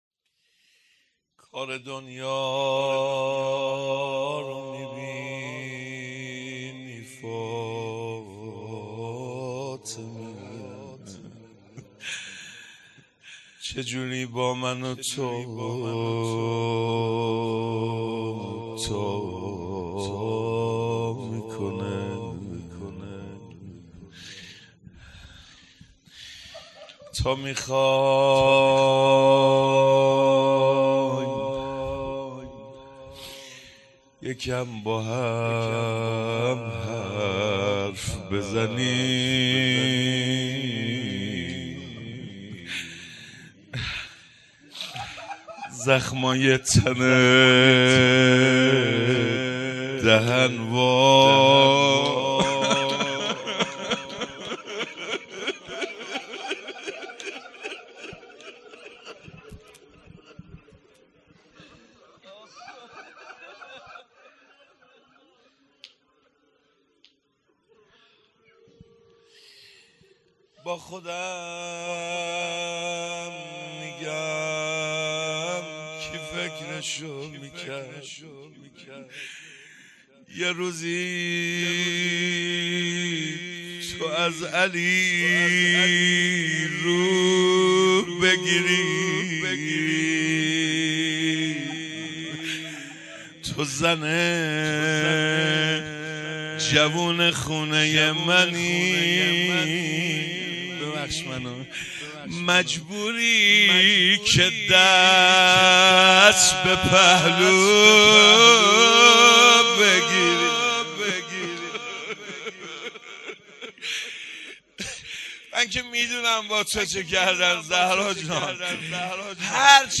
روضه
شب سوم مراسم شهادت حضرت فاطمه زهرا سلام الله علیها آبان ۱۴۰۳